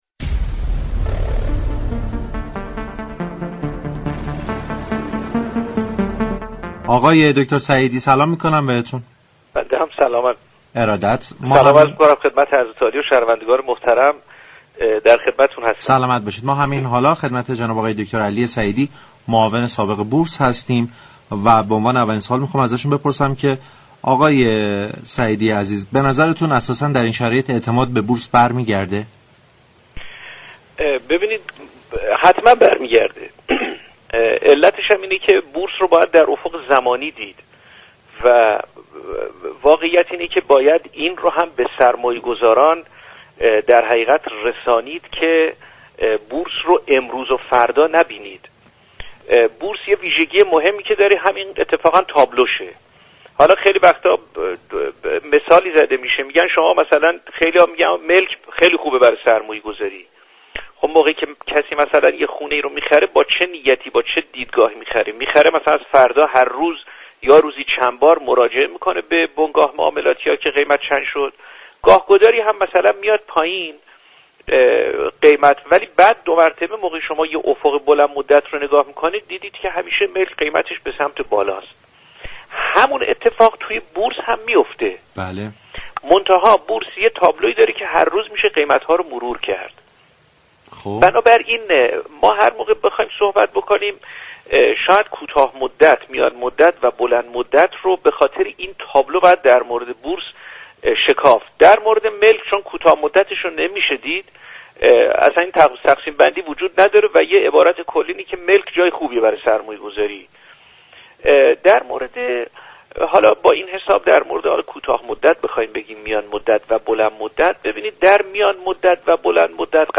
در گفتگو با برنامه بازار تهران